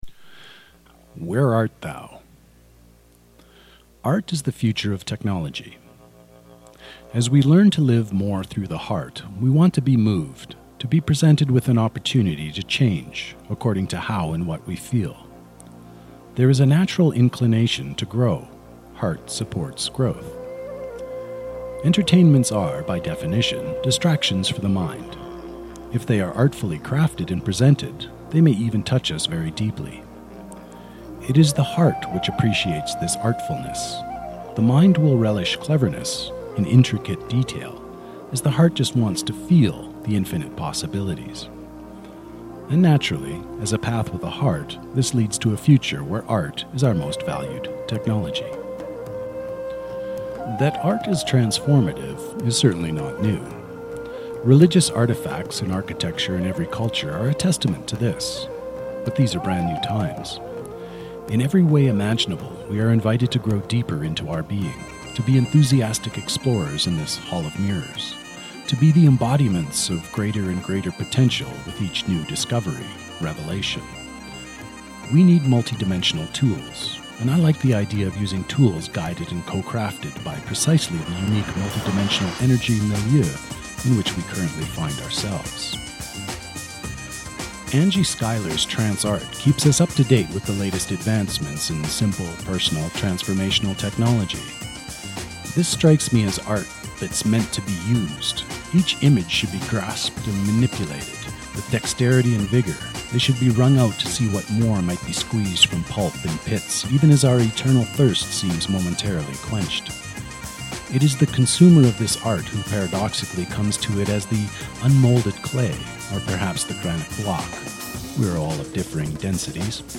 (Author Narration with musical accompaniment: Busindre Reel by Hevia)